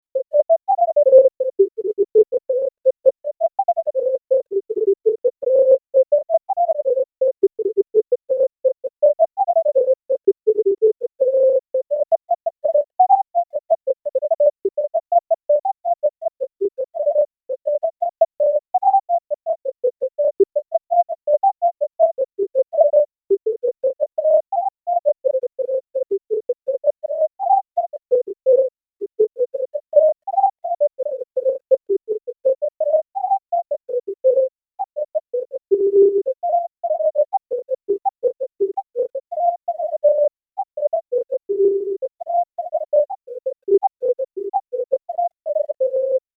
These require an extra stage of processing for paleospectrophony: Engramelle showed the sounded and unsounded parts of each note with different shadings, so the unsounded parts need to be erased.
Romance by Claude Balbastre, pinned as played by the composer, duration specified to the second: